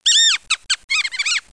00223_Sound_Mouse.mp3